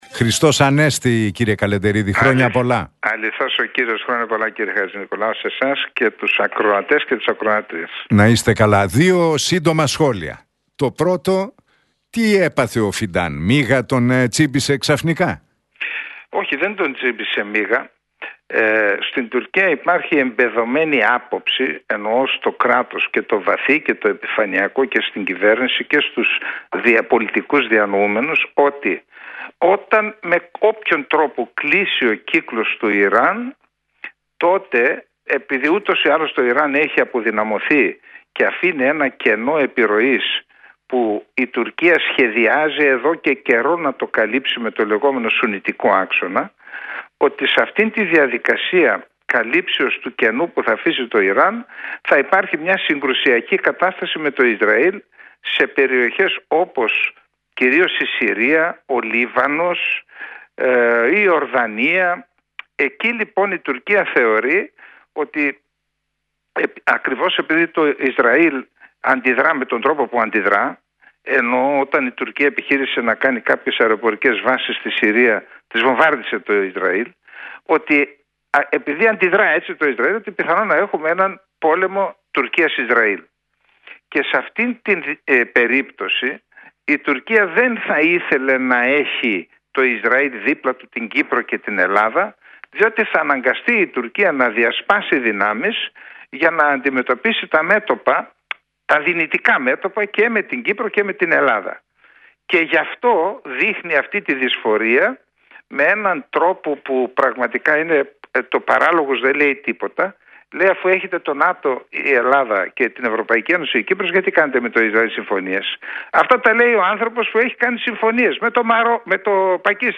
Ο Σάββας Καλεντερίδης μίλησε για τις τελευταίες εξελίξεις στη Μέση Ανατολή αλλά και για τις προκλητικές δηλώσεις Φιντάν για τη συνεργασία Ισραήλ- Ελλάδας- Κύπρου στην εκπομπή του Νίκου Χατζηνικολάου στον Realfm 97,8.